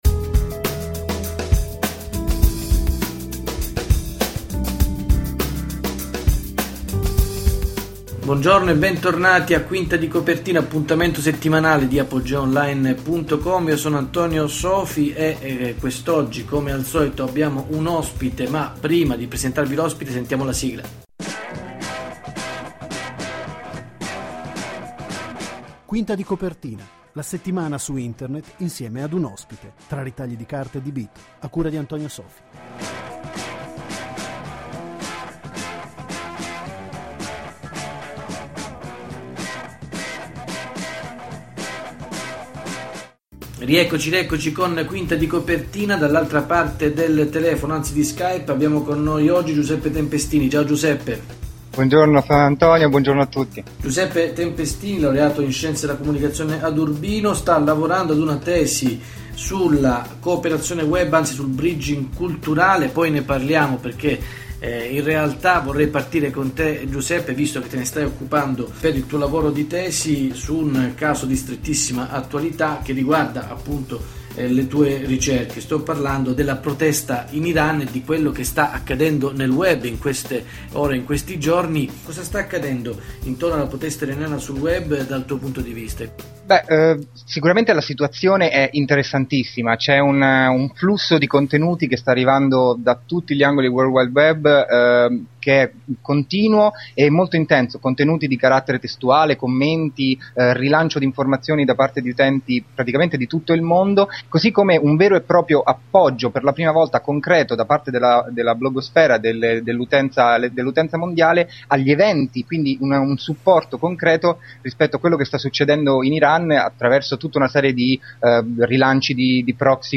Venti minuti in podcast tra ritagli di carta e bit, in compagnia di un ospite.